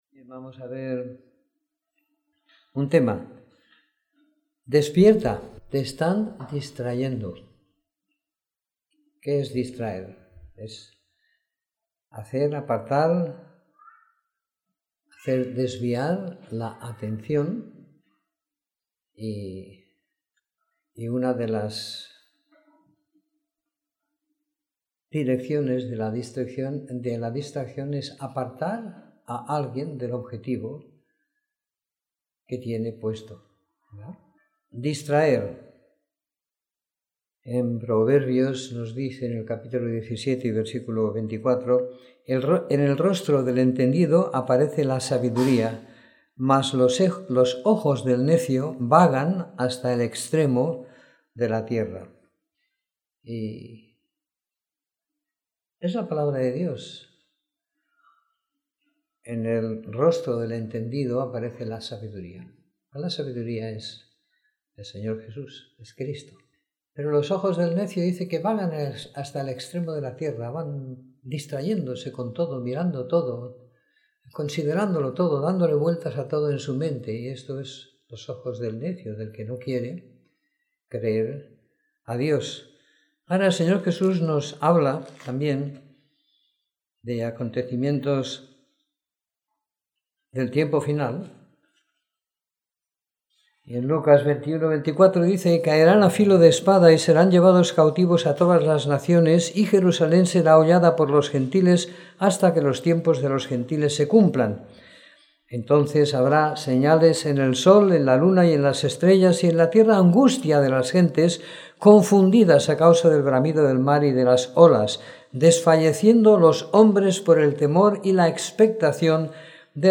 Domingo por la Mañana . 10 de Julio de 2016